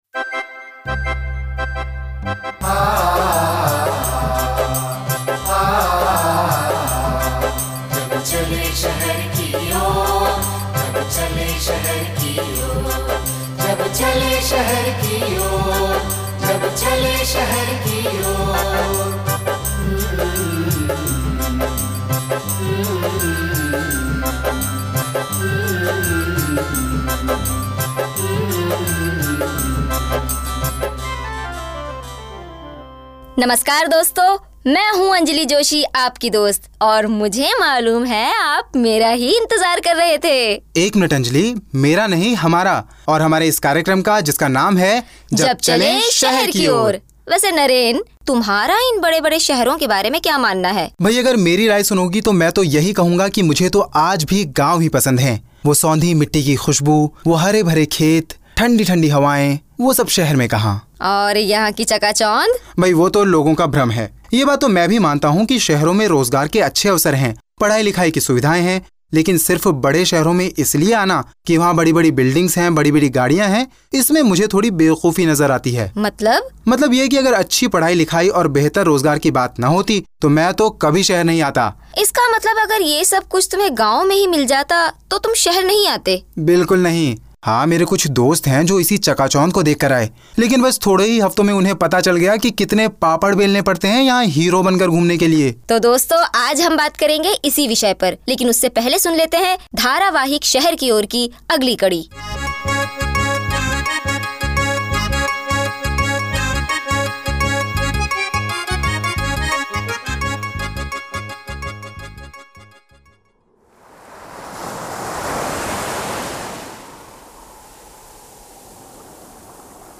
Jab Chale Sheher Ki Ore ( Towards the City ) RADIO/52 EPISODES/28 MINS EACH/HINDI Year: 2007 - 2009 Supported by: Equal Access/The Ford Foundation A magazine radio show on safe migration, developed under the New Voices Safe Migration initiative . Directed at migrant communities living in Mumbai and Delhi, the show was broadcast on All India Radio (AIR) FM Rainbow in Delhi and Mumbai, and on AIR MW Najibabad.